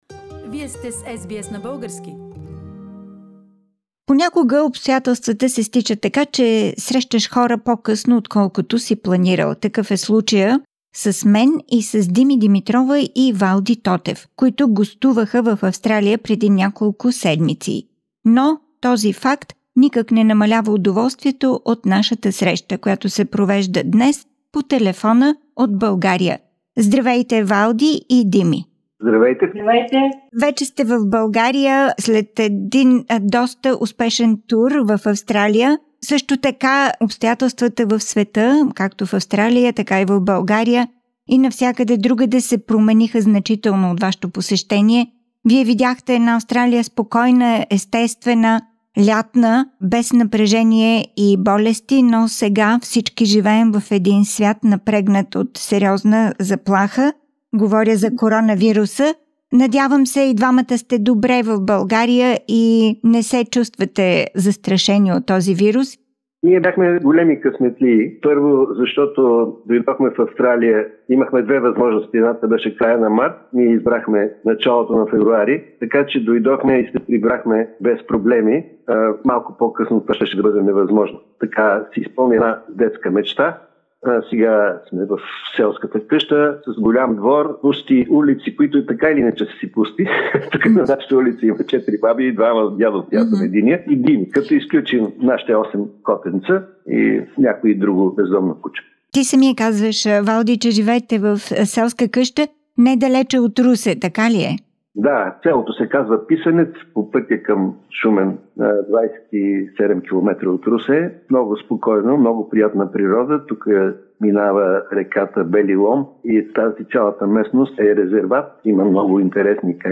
Interview with duet ValDi